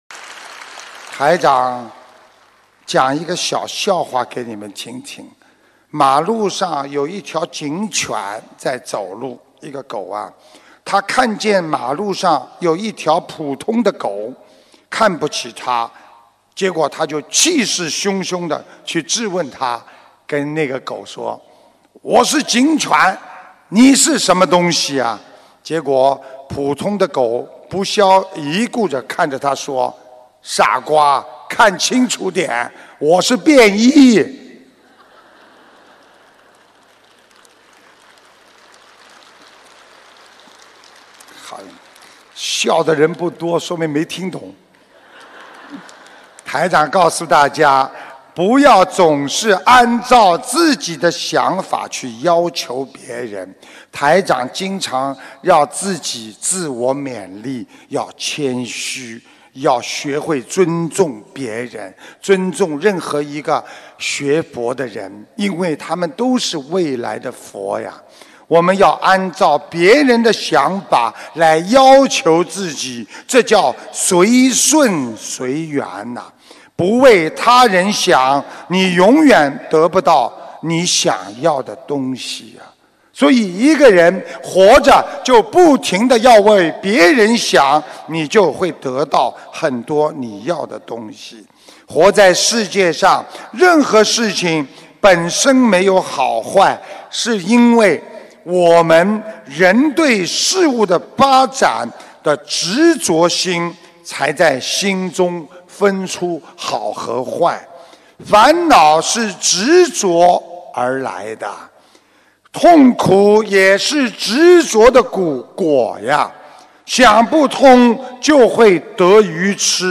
音频：警犬与狗·师父讲笑话